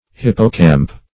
Hippocamp \Hip"po*camp\, n.